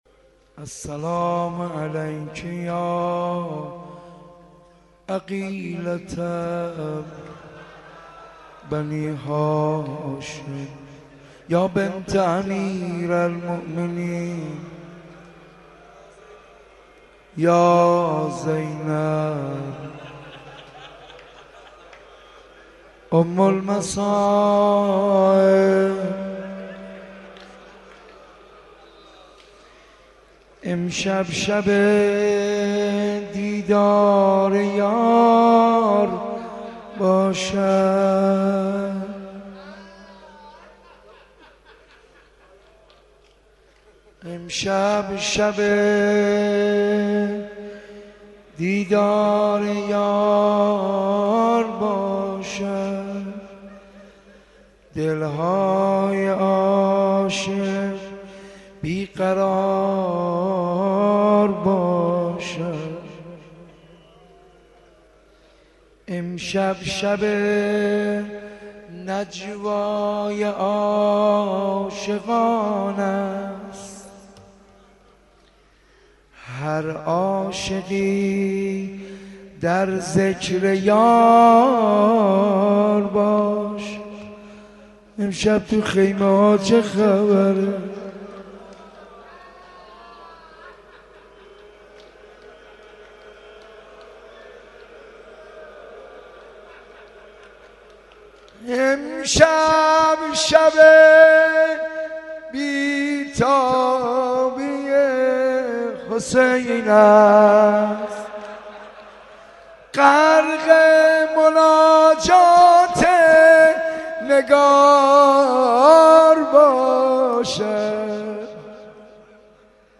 shabe10 moharram 82 ark.mp3